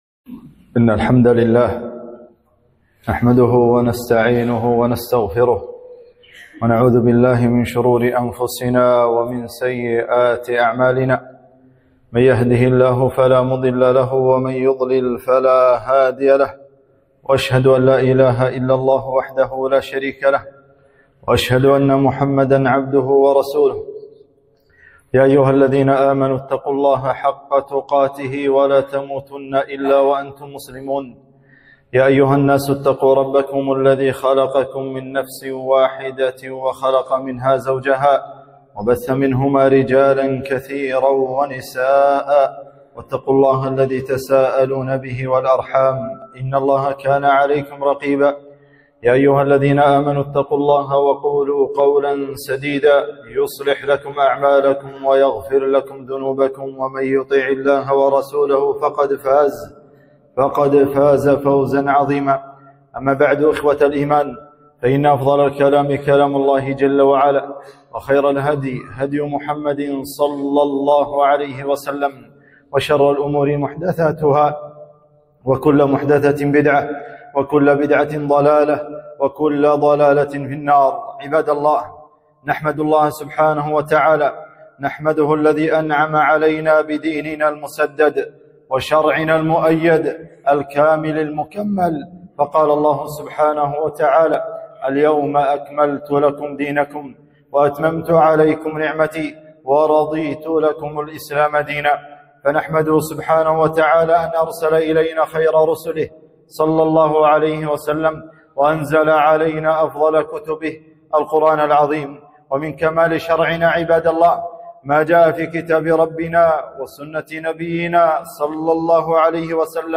خطبة - عيد الكريسماس عيد النصارى